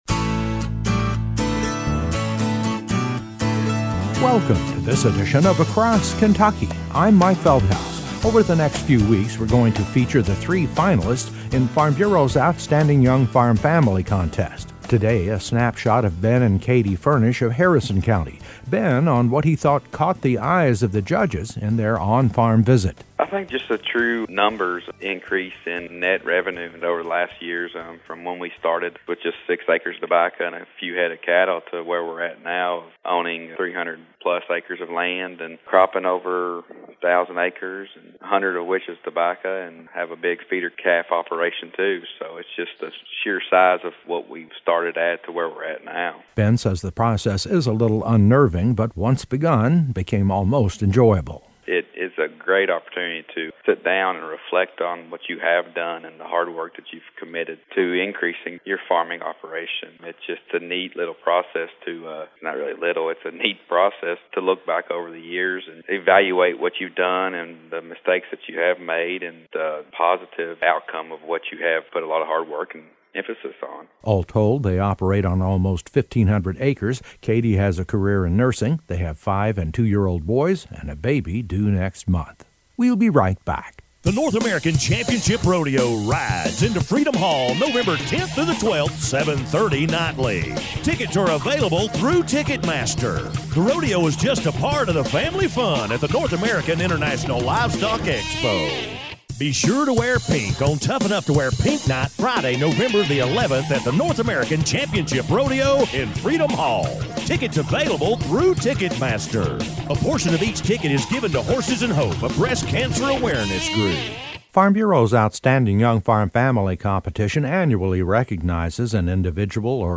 A feature report